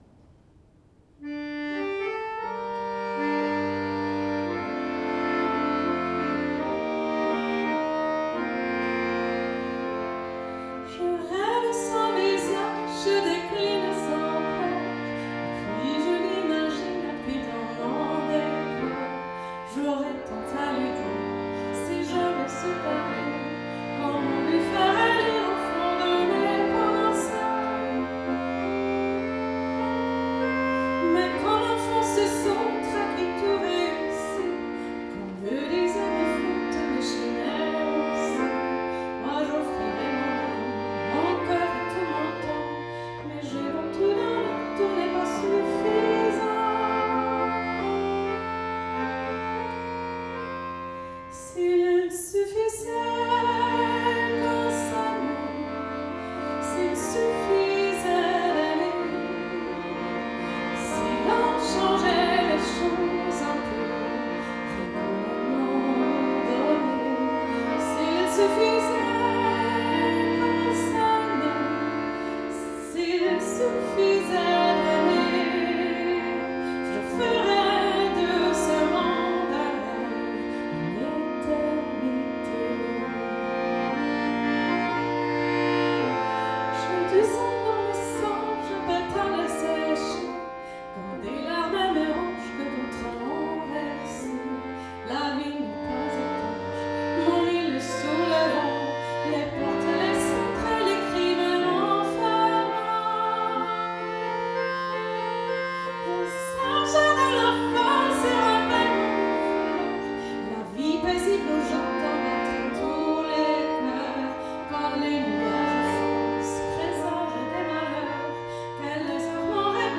Akkordeon & Gesang